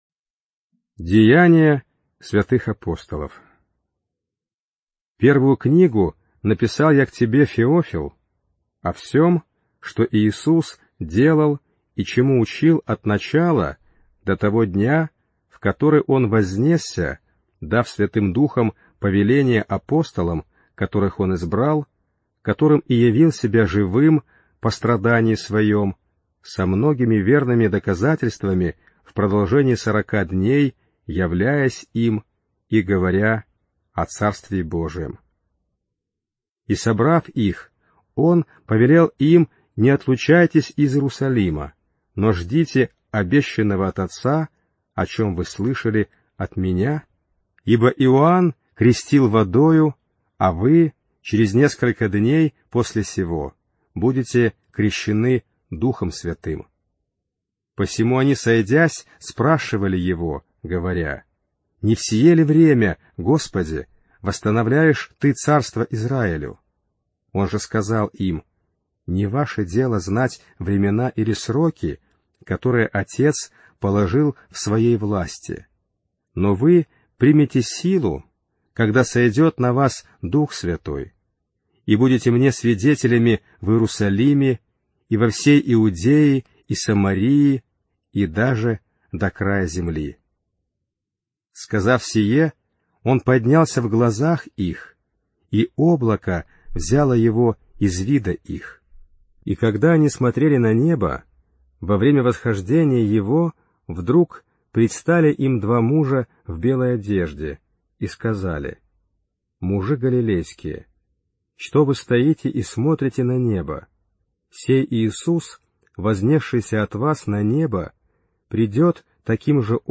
ЖанрХристианство